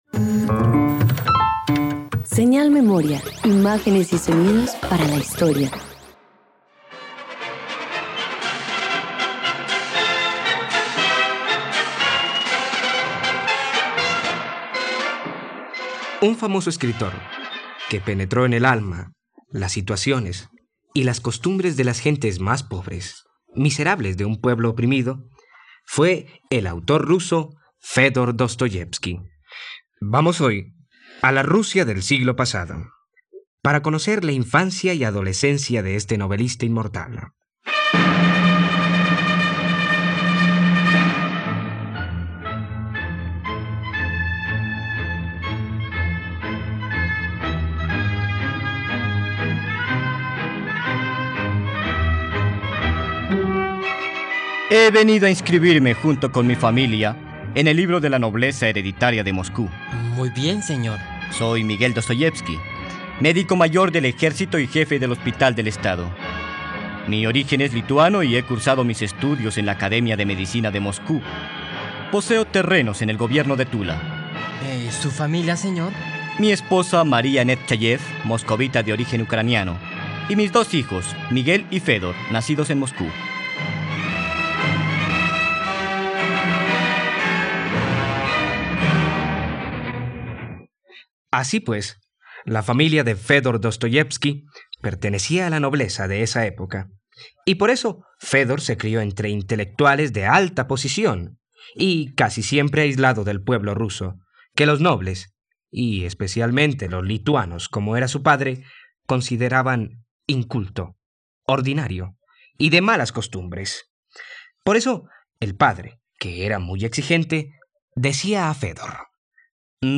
Dostoyevski - Radioteatro dominical | RTVCPlay